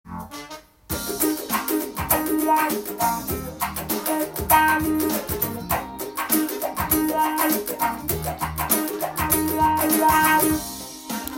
ワウペダルが特に合うジャンルは、「ファンク」の曲になります。
管楽器と１６ビート、ギターのワウペダルが合わさった時
ファンク独特のグルーブ感が生まれます。